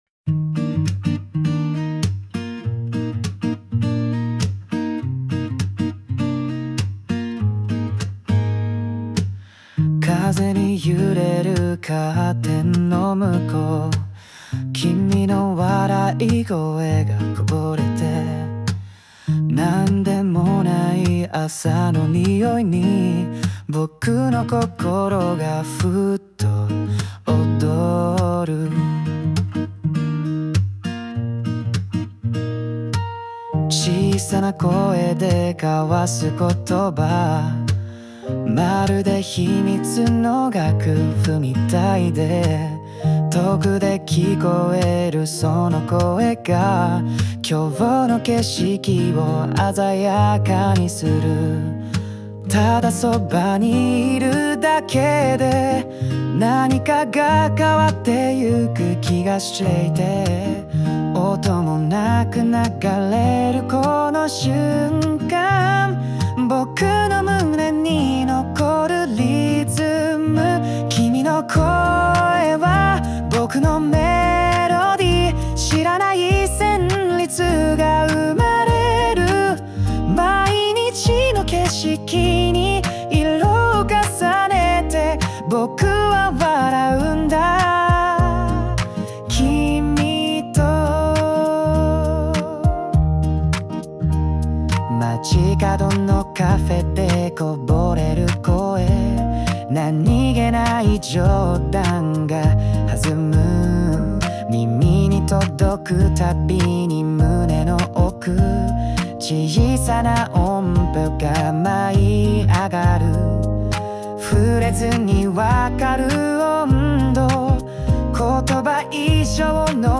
邦楽男性ボーカル著作権フリーBGM ボーカル
著作権フリーオリジナルBGMです。
男性ボーカル（邦楽・日本語）曲です。